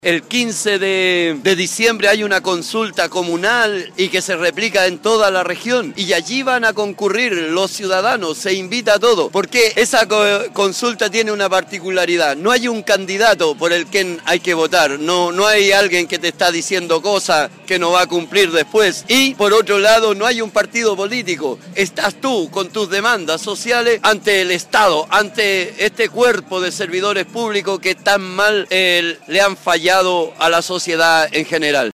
DESPACHO-MARCHA-COPIAPO-2.mp3